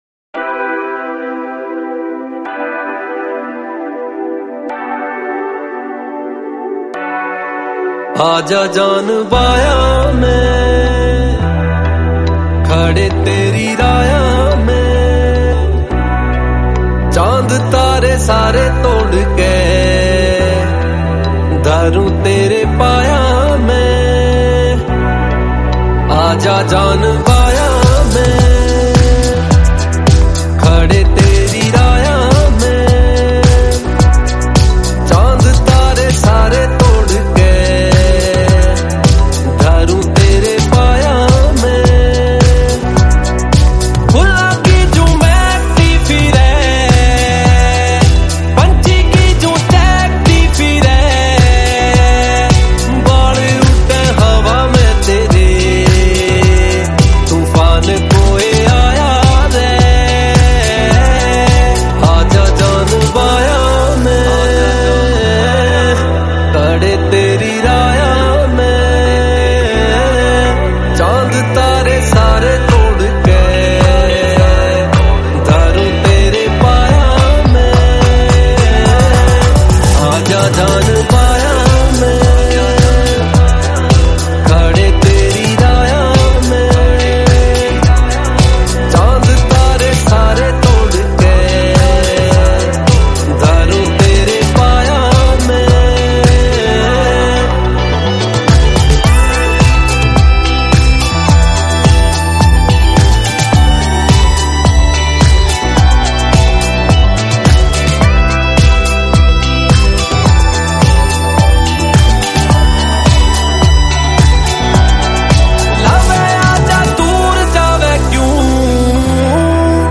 Category: Haryanvi